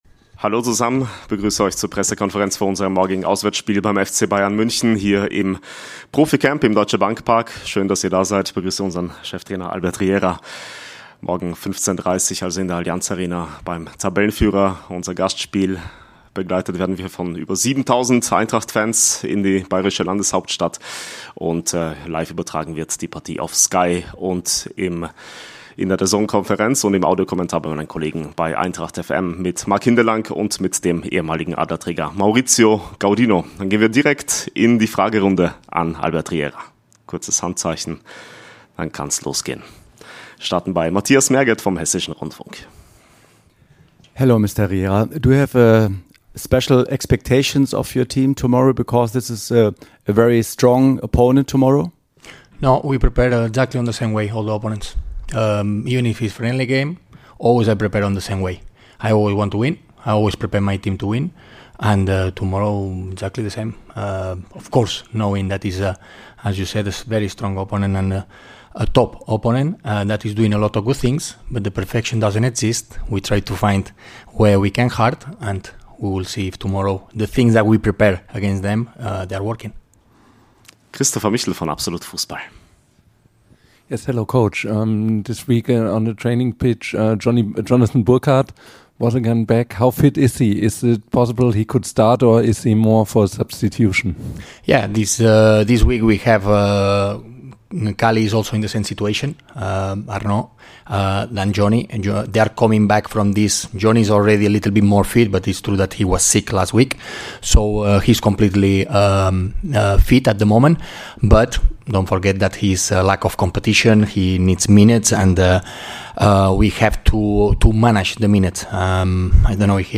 Die Pressekonferenz vor dem Bundesliga-Auswärtsspiel beim Tabellenführer mit Cheftrainer Albert Riera.